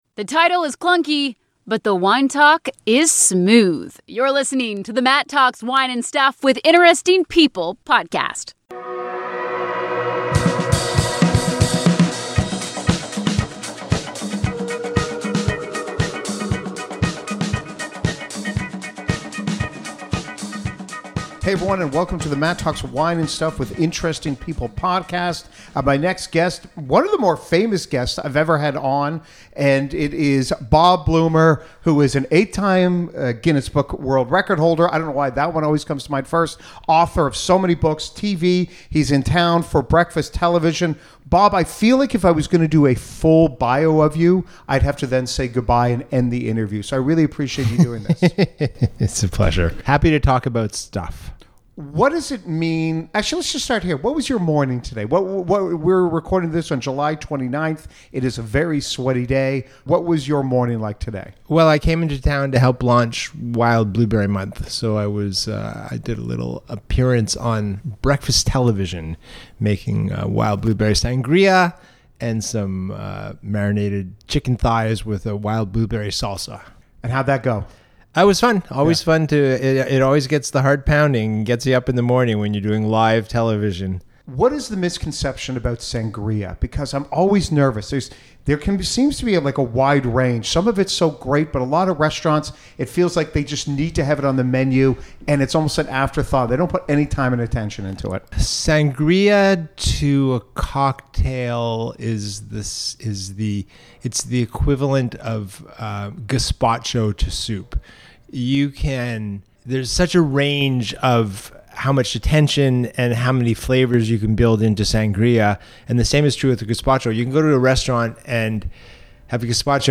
My interview with Bob Blumer!